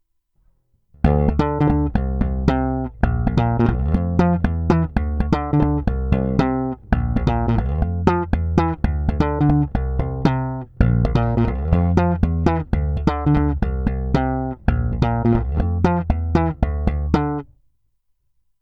Slapem se na tuhle basu moc hrát nedá, a nebo to neumím, slapovat jsem musel daleko od krku až mezi snímači.
Slap na oba snímače – není to ono, že?